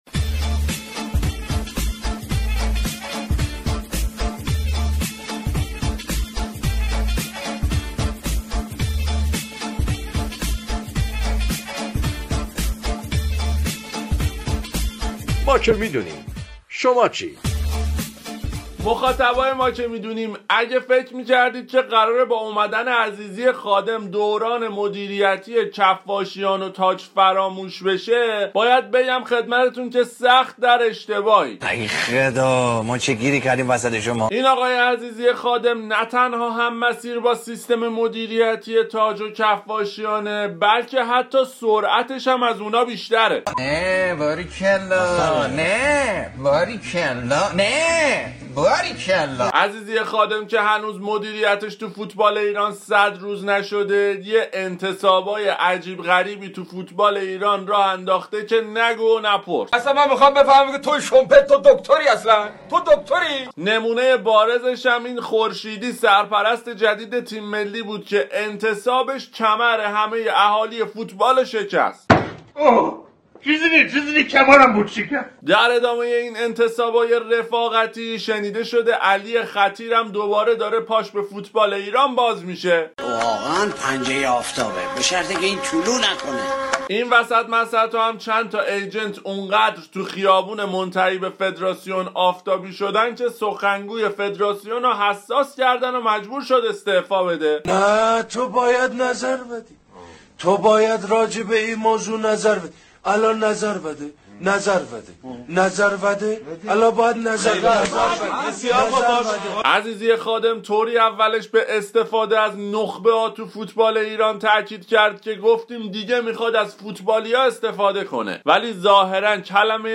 سوژه اصلی آیتم طنز ما که می دونیم برنامه از فوتبال چه خبر رادیو ورزش، انتصابات شهاب الدین عزیزی خادم برای پست های مختلف فدراسیون فوتبال بود